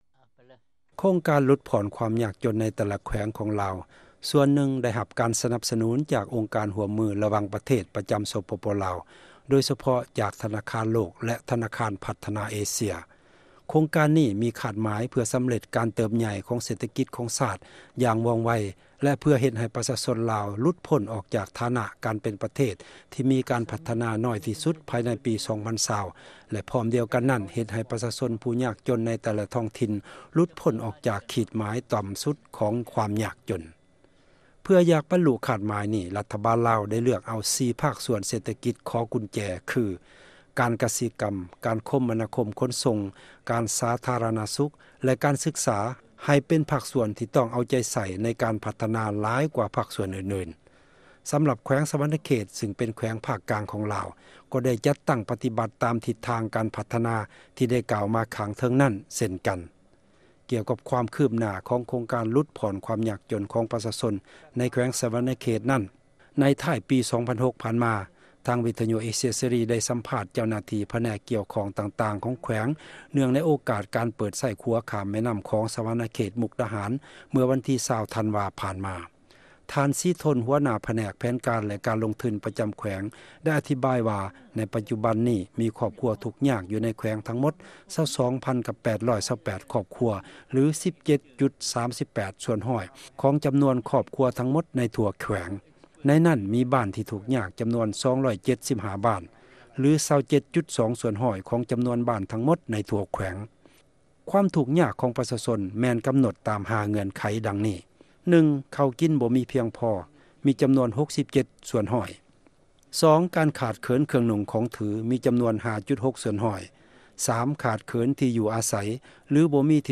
ສຳລັບ ແຂວງສວັນະເຂດ ຊື່ງເປັນແຂວງ ພາກກາງ ຂອງລາວ ກໍໄດ້ຈັດຕັ້ງ ປະຕິບັດ ຕາມທິດທາງ ການພັດທະນາ ທີ່ໄດ້ກ່າວມາ ຂ້າງເທິງນັ້ນ ເຊັ່ນກັນ. ກ່ຽວກັບ ຄວາມຄືບໜ້າ ຂອງໂຄງການ ຫລຸດຜ່ອນ ຄວາມຍາກຈົນ ຂອງປະຊາຊົນ ໃນ ແຂວງ ສວັນະເຂດນັ້ນ ໃນ ທ້າຍປີ 2006 ຜ່ານມາ ທາງວິທະຍຸເອເຊັຽເສຣີ ໄດ້ ສຳພາດ ເຈົ້າໜ້າທີ່ ຜະແນກກ່ຽວຂ້ອງຕ່າງໆ ຂອງແຂວງ ເນື່ອງໃນ ໂອກາດ ການເປີດ ໄຊ້ຂົວຂ້າມ ແມ່ນ້ຳຂອງ ສວັນະເຂດ-ມຸກດາຫານ ເມື່ອວັນທີ 20 ທັນວາ ຜ່ານມາ.